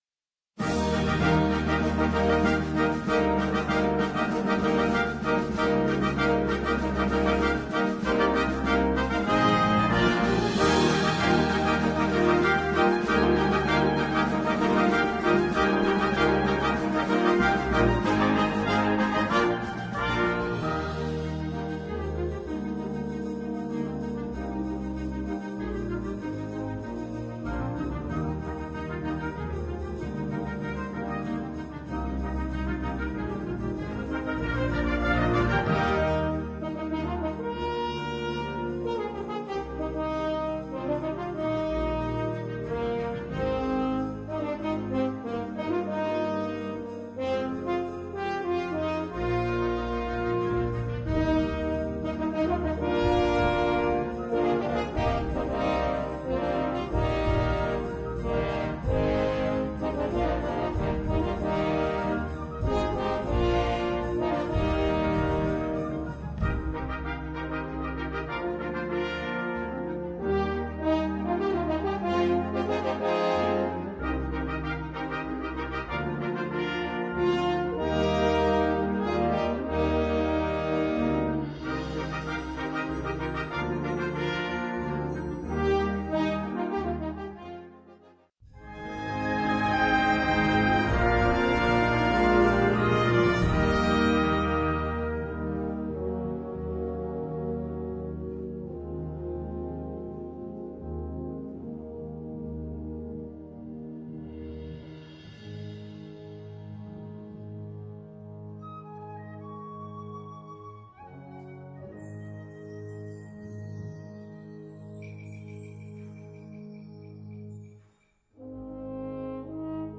Gattung: Solo für 3 oder 4 Hörner und Blasorchester
Besetzung: Blasorchester